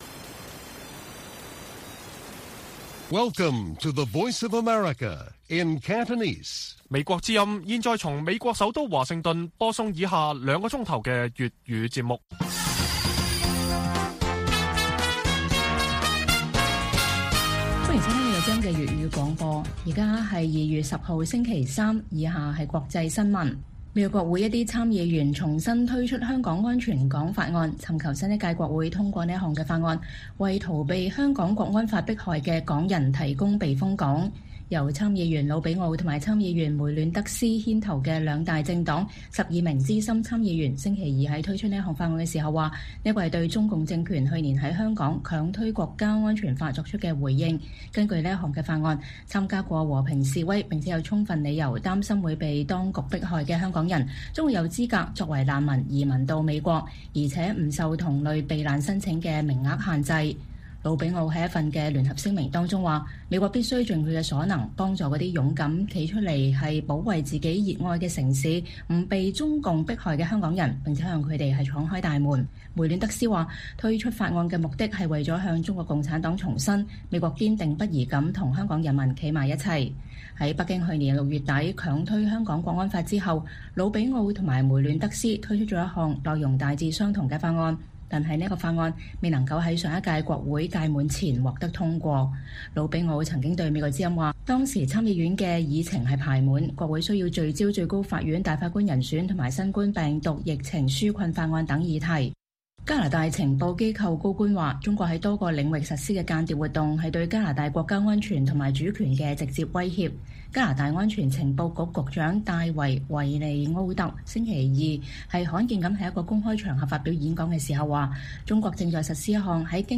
粵語新聞 晚上9-10點